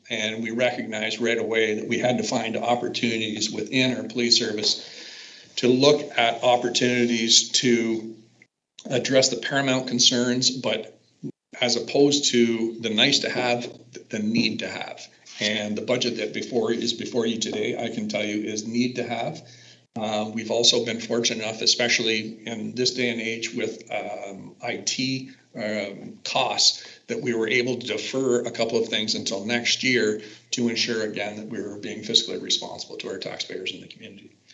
Belleville Police Service Chief Mike Callaghan kicked off the budget discussion at the board’s meeting on Monday saying that they began with a budget of $1.18 million.
Chief Callaghan explained how the final number came together.